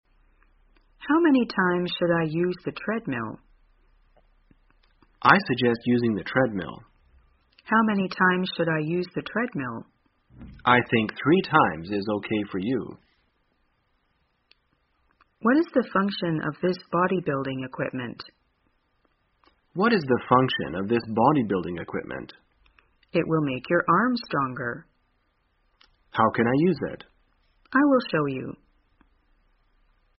在线英语听力室生活口语天天说 第201期:怎样询问健身器械的听力文件下载,《生活口语天天说》栏目将日常生活中最常用到的口语句型进行收集和重点讲解。真人发音配字幕帮助英语爱好者们练习听力并进行口语跟读。